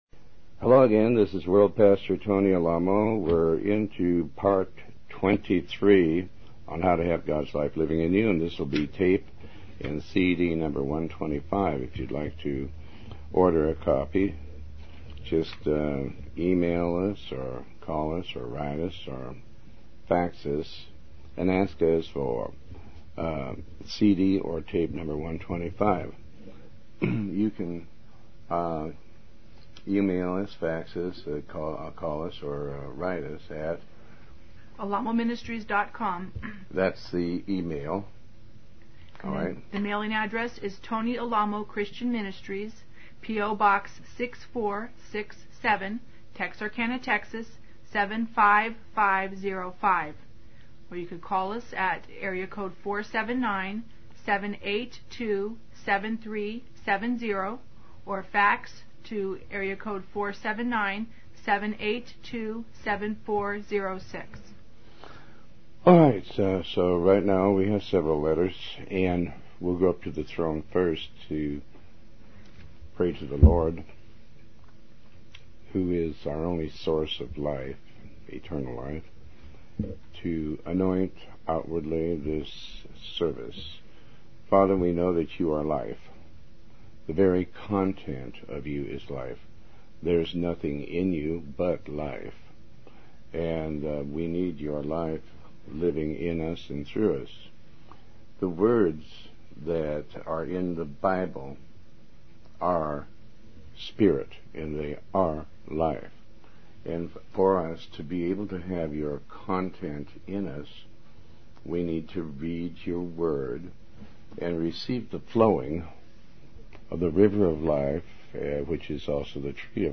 Talk Show Episode, Audio Podcast, Tony Alamo and Ep125, How To Have Gods Life Living In You, Part 23 on , show guests , about How To Have Gods Life Living In You, categorized as Health & Lifestyle,History,Love & Relationships,Philosophy,Psychology,Christianity,Inspirational,Motivational,Society and Culture